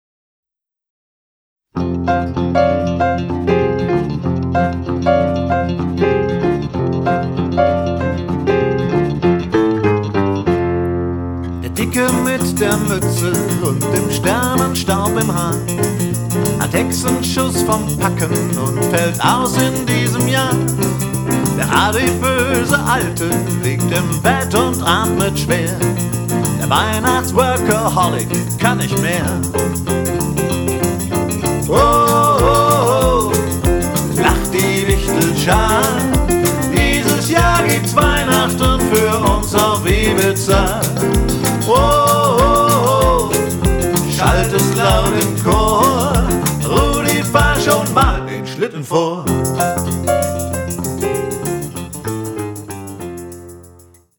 Piano, Keyboards
Percussion
Vocals, Gitarren